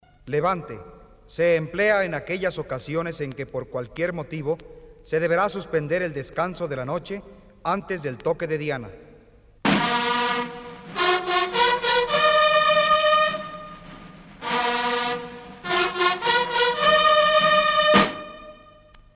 TOQUES MILITARES REGLAMENTARIOS EN MP3.